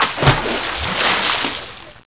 j_swim2.wav